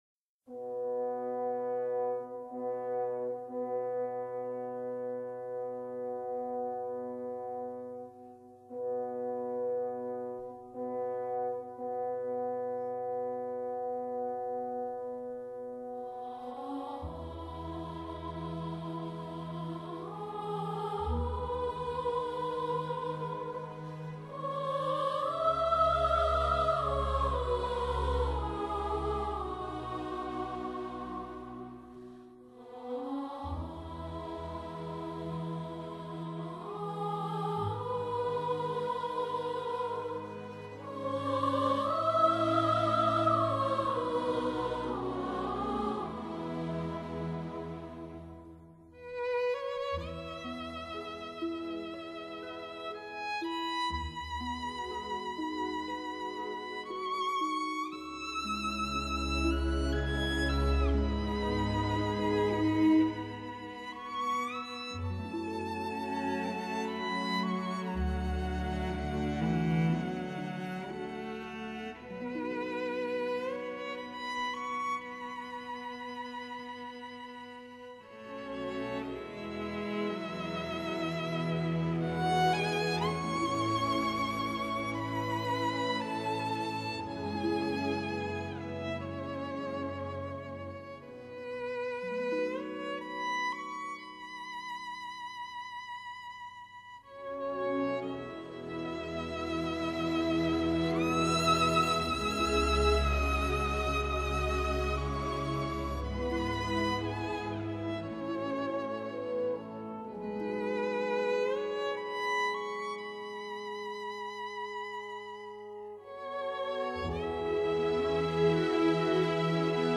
大型舞剧交响